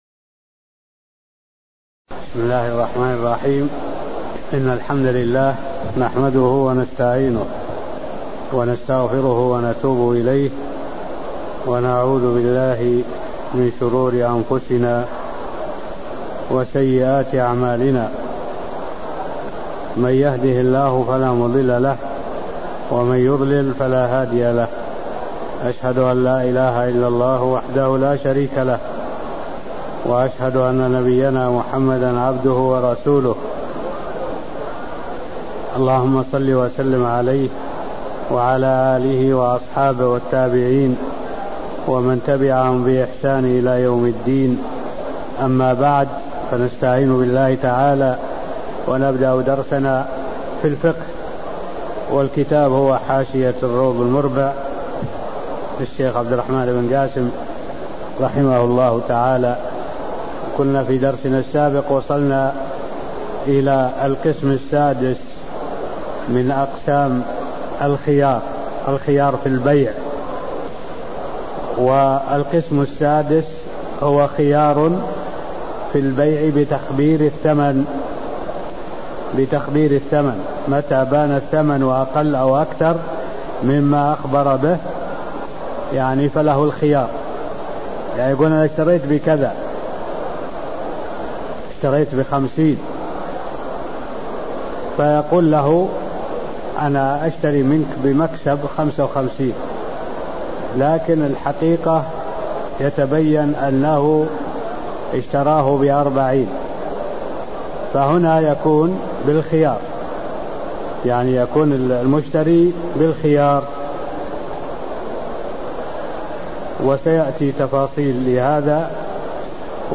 المكان: المسجد النبوي الشيخ: معالي الشيخ الدكتور صالح بن عبد الله العبود معالي الشيخ الدكتور صالح بن عبد الله العبود باب الخيارخيار التخبير بالثمن (07)